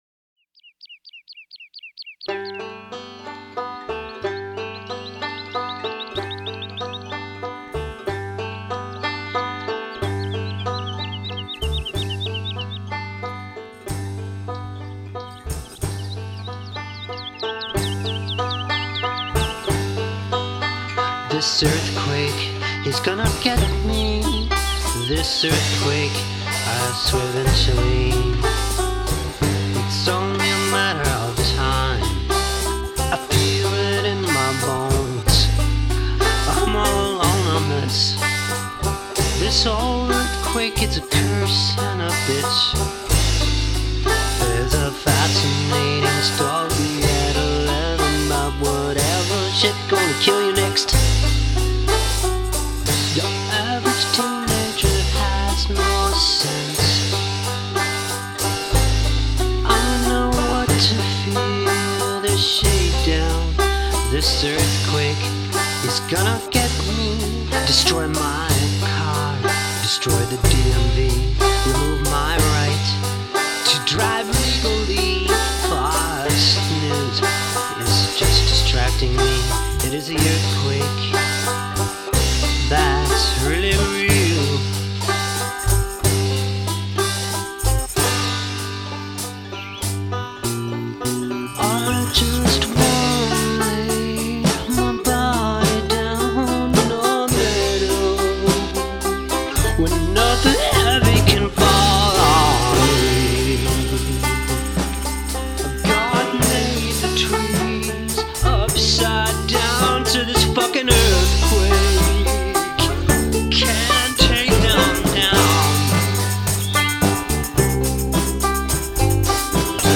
folk experimental music